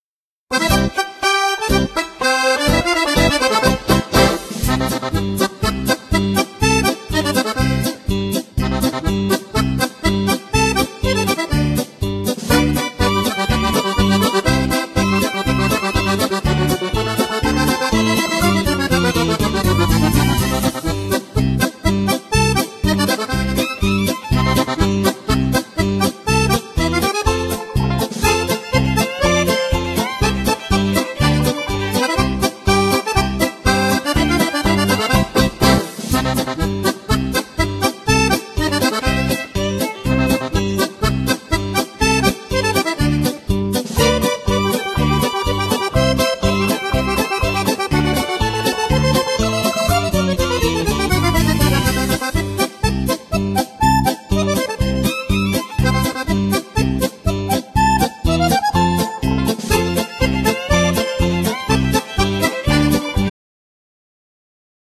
Genere : Folk